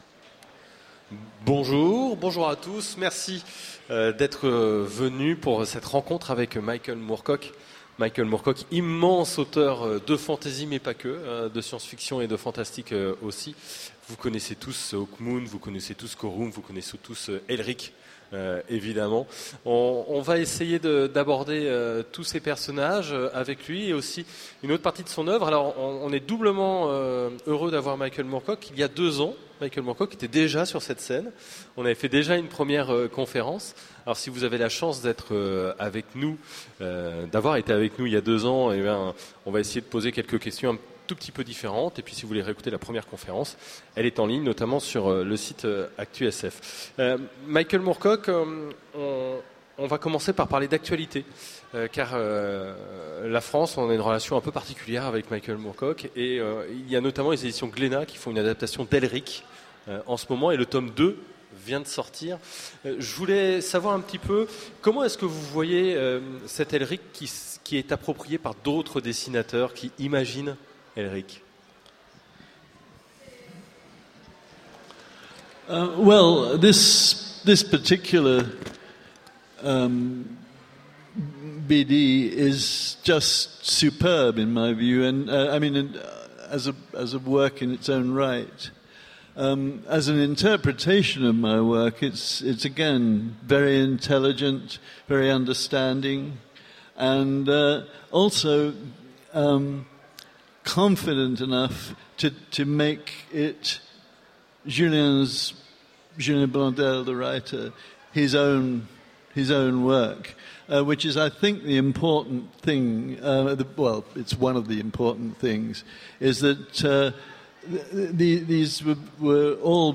Utopiales 2014 : Rencontre avec Michael Moorcock
- le 31/10/2017 Partager Commenter Utopiales 2014 : Rencontre avec Michael Moorcock Télécharger le MP3 à lire aussi Michael Moorcock Genres / Mots-clés Rencontre avec un auteur Conférence Partager cet article